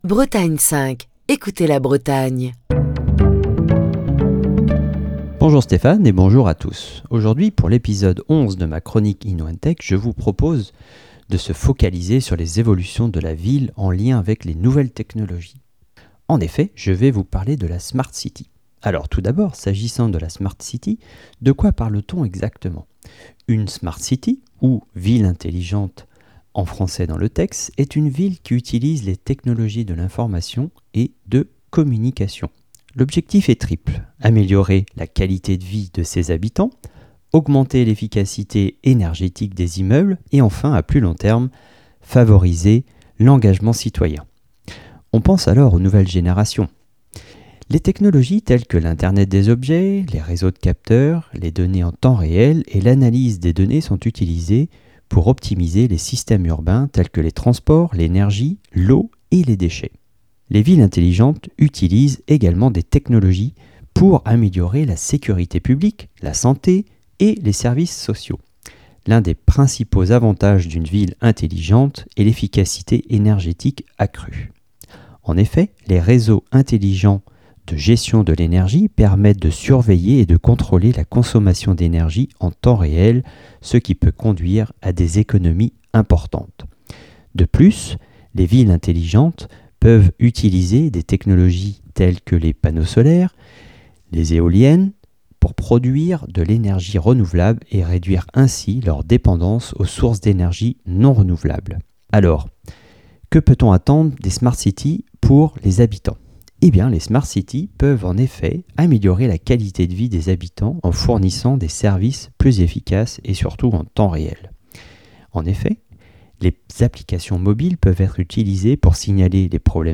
Chronique du 12 avril 2023. Voilà une dizaine d'années qu'est apparu le concept de smart city (ou ville intelligente), avec un objectif : améliorer la vie des citadins dans leur quotidien grâce à l'apport des nouvelles technologies.